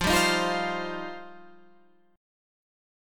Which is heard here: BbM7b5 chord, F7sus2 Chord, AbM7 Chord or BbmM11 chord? F7sus2 Chord